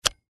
На этой странице вы найдете подборку звуков, связанных с работой фонариков: щелчки кнопок, гудение светодиодов, шум переключателей.
Шум отключения фонаря